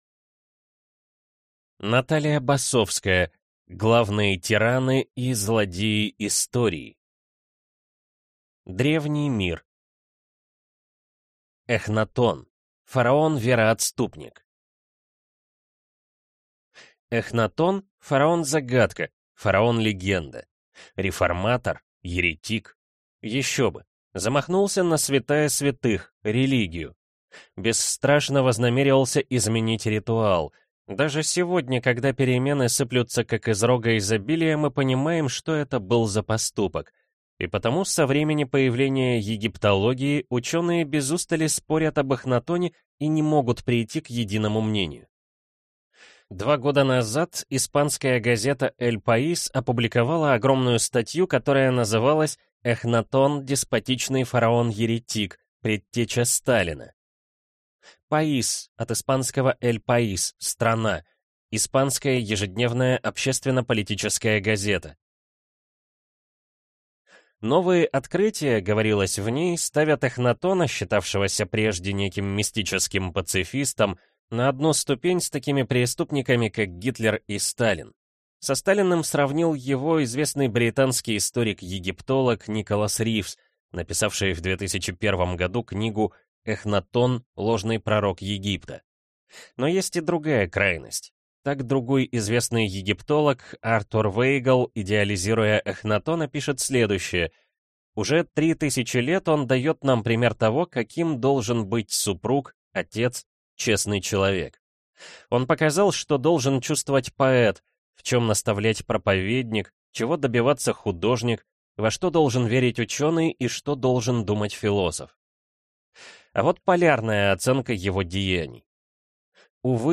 Аудиокнига Главные тираны и злодеи истории | Библиотека аудиокниг